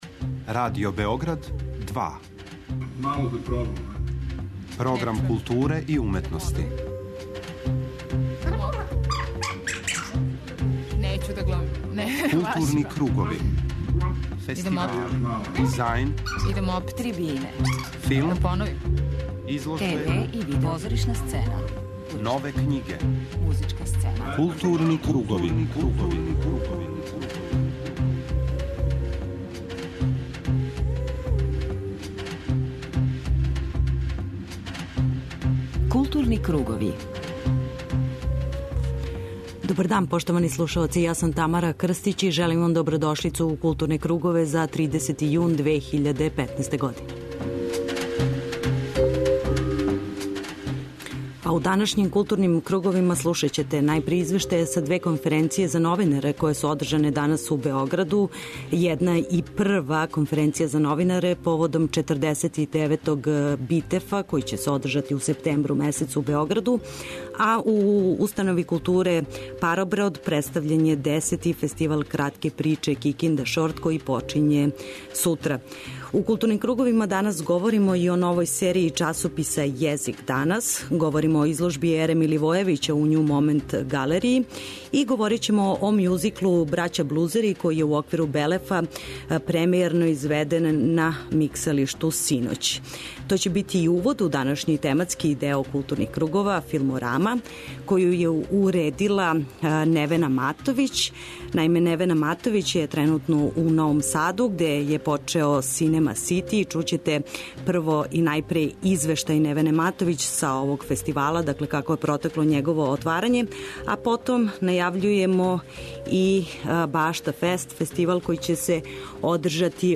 преузми : 51.14 MB Културни кругови Autor: Група аутора Централна културно-уметничка емисија Радио Београда 2.